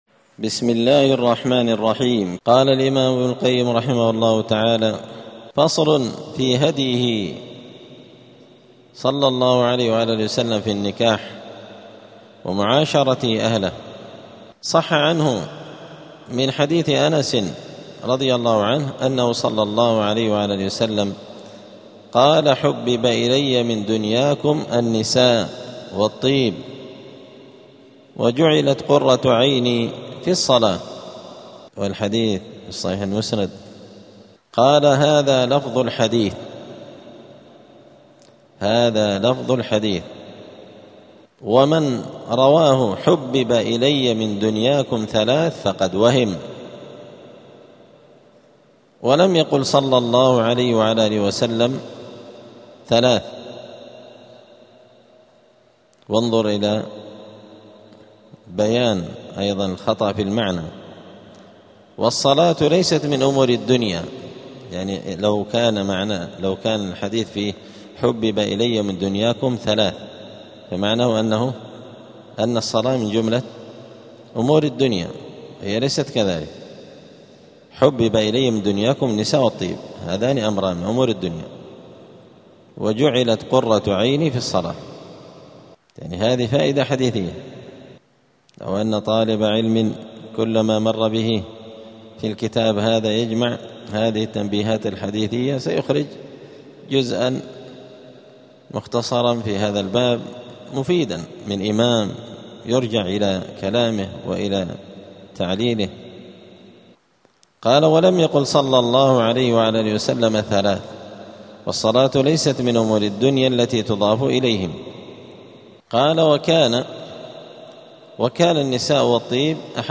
الجمعة 22 شوال 1447 هــــ | الدروس، دروس الآداب، زاد المعاد في هدي خير العباد لابن القيم رحمه الله | شارك بتعليقك | 2 المشاهدات